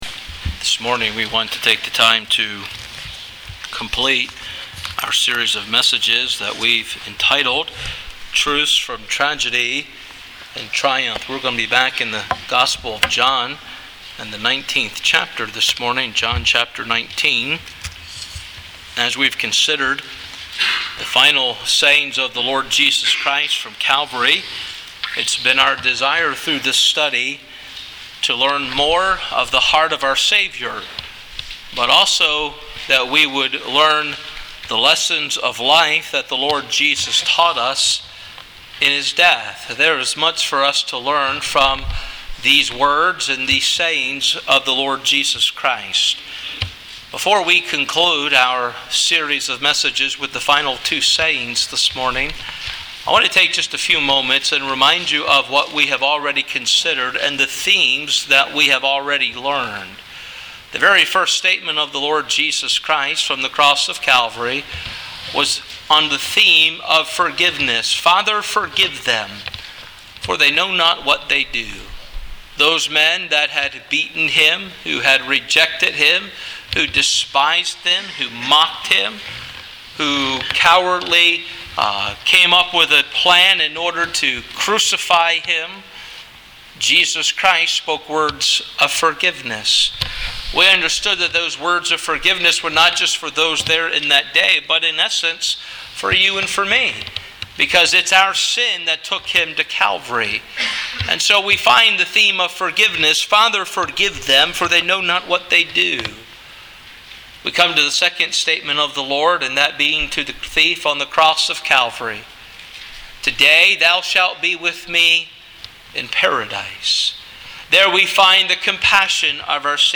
Sermon MP3
Victory is Complete_Good Friday.mp3